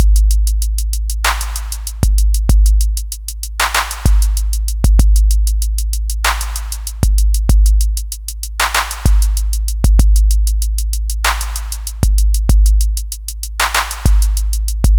I decided to run a quick comparison between programming a 2 bar drum loop via the Akai MPC 3000 & doing the same via Protools/midi track & a software sampler.
The swing is very close but you can hear a difference with the hats & dual snare hits.